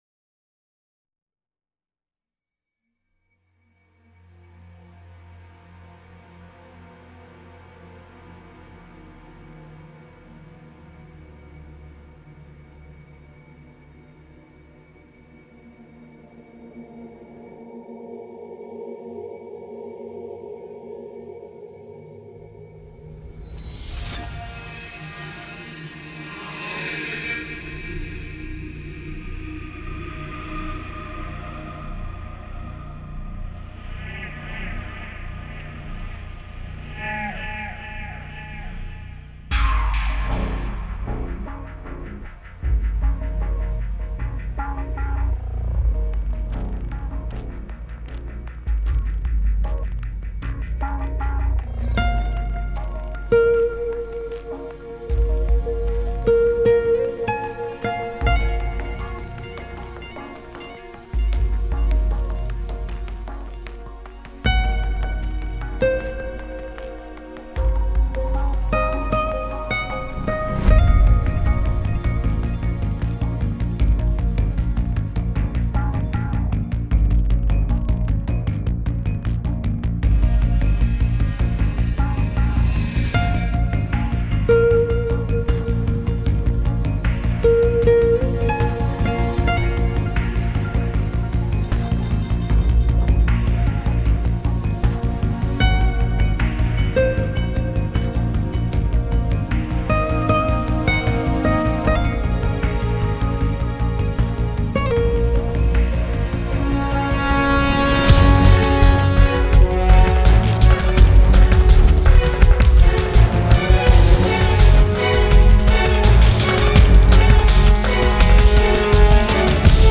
موسیقی متن موسیقی بیکلام